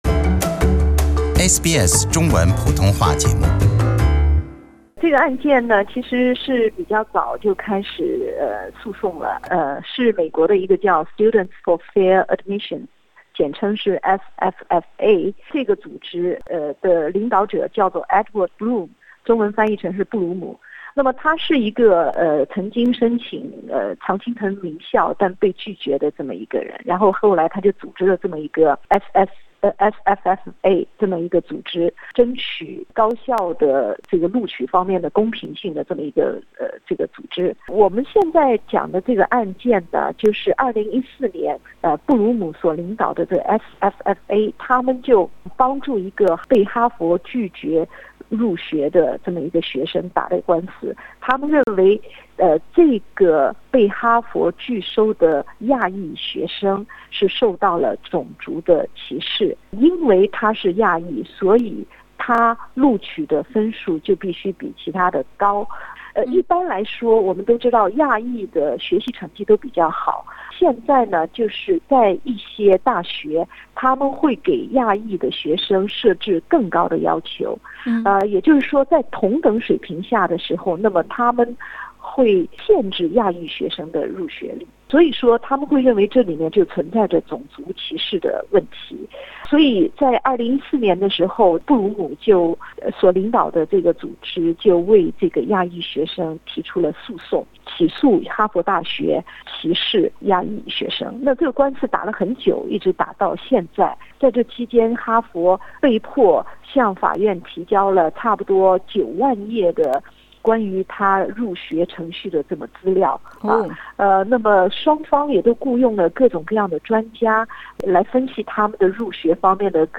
（本节目为嘉宾观点，不代表本台立场。）